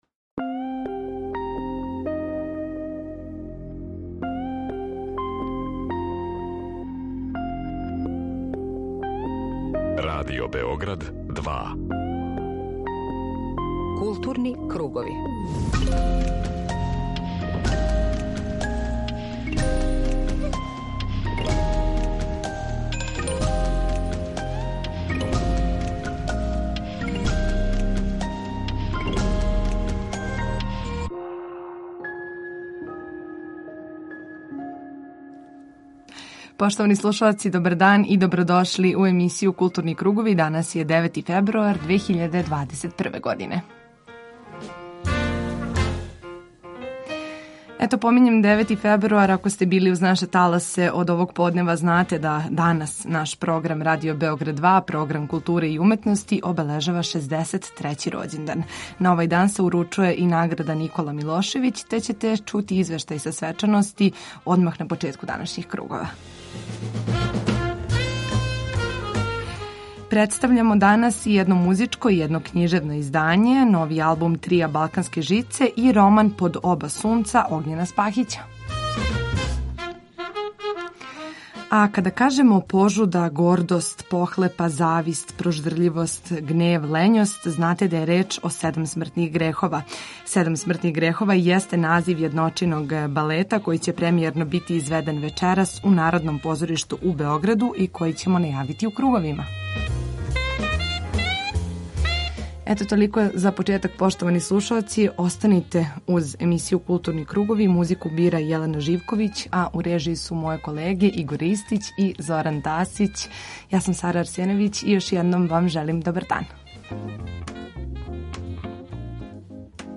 У емисији Културни кругови данас преносимо део атмосфере са обележавања 63. рођендана нашег програма када се традиционално уручује Награда „Никола Милошевић" за најбољу књигу из области теорије књижевности и уметности, естетике и филозофије.